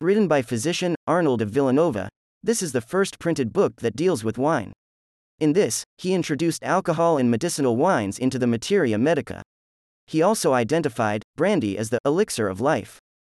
1. View - Audio Desciption: